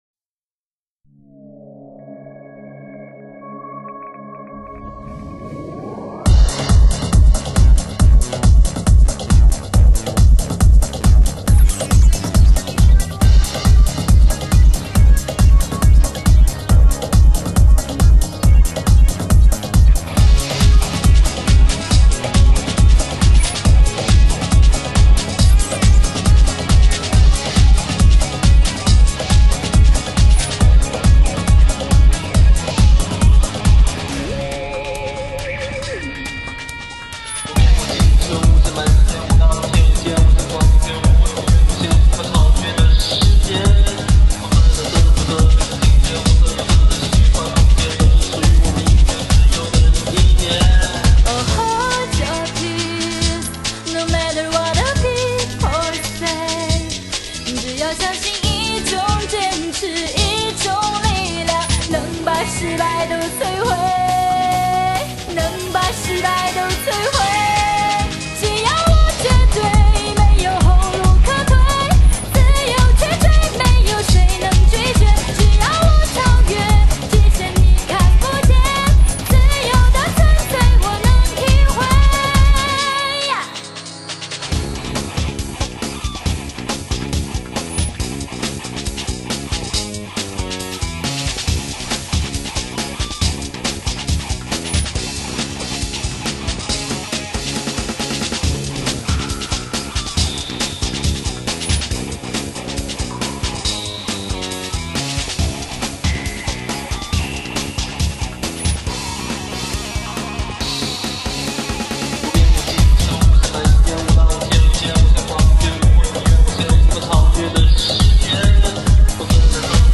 动感实足，OK！
的确动感十足啊~~